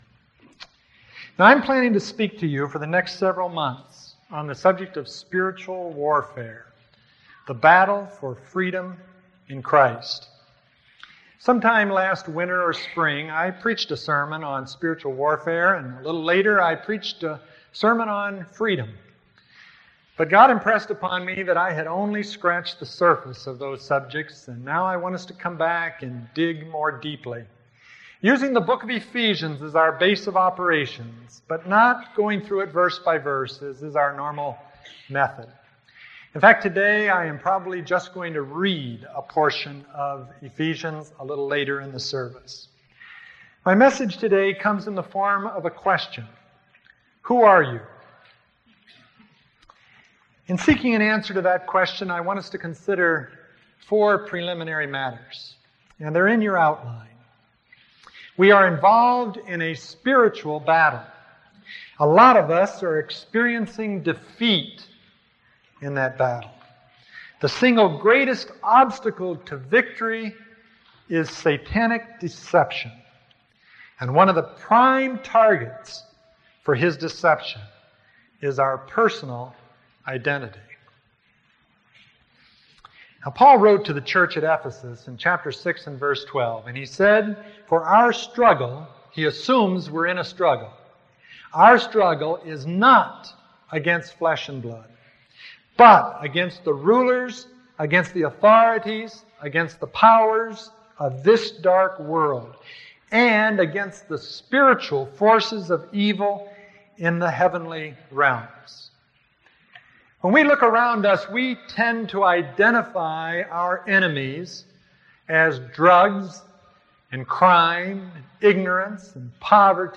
Note to reader: These sermons on Ephesians are not my typical verse-by-verse exposition.